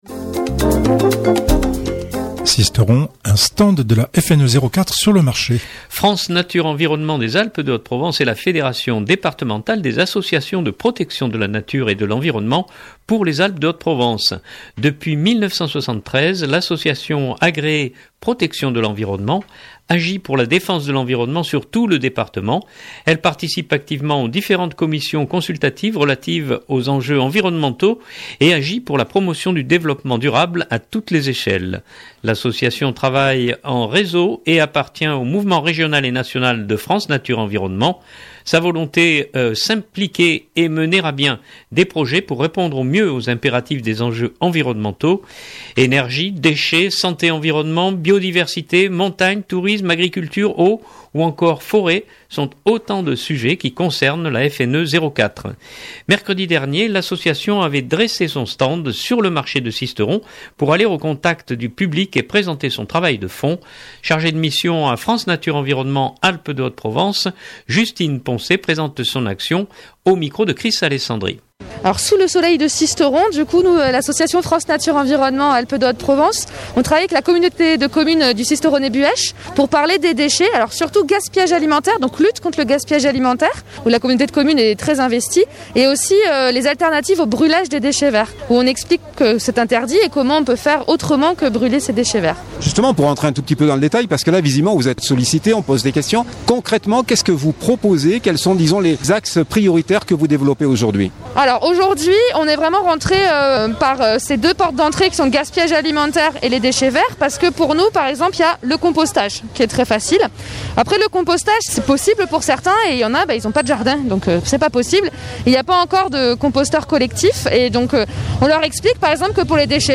Mercredi dernier, l’association avait dressé son stand sur le marché de Sisteron pour aller au contact du public et présenter son travail de fond.